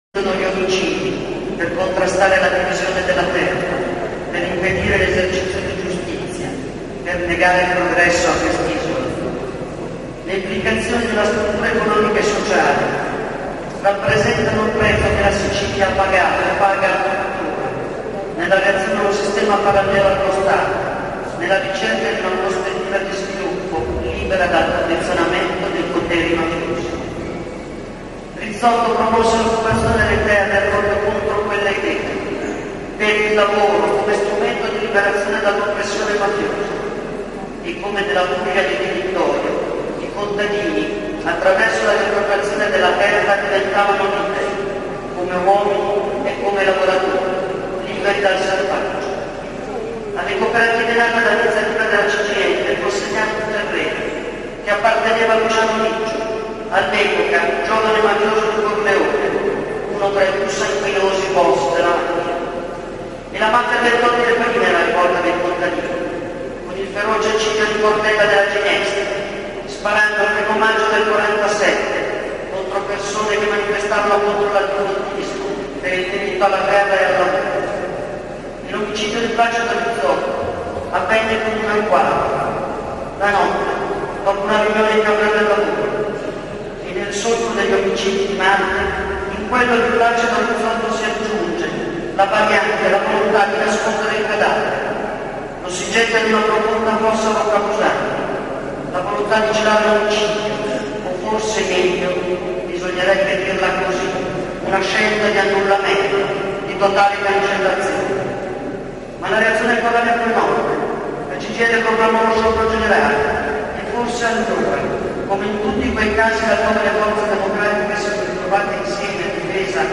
Guarda il video Susanna Camusso segretario generale CGIL 5' 19'' 450 Scarica mp3